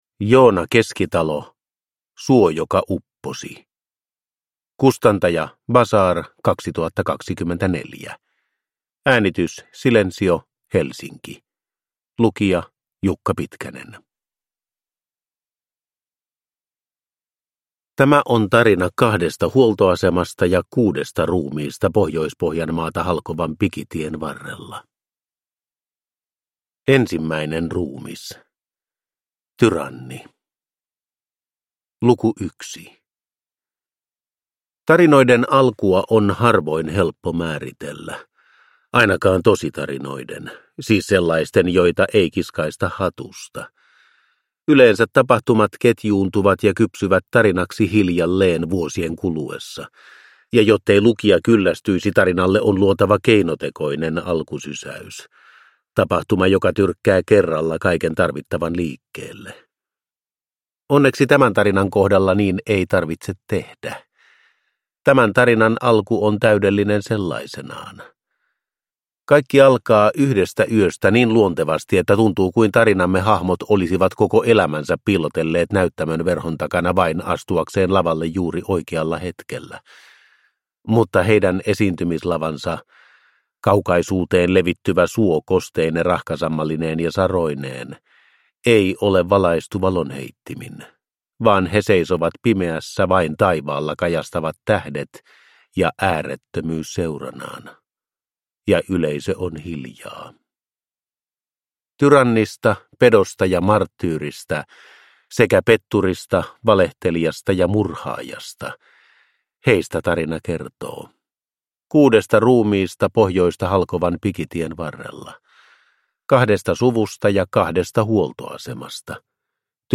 Suo, joka upposi – Ljudbok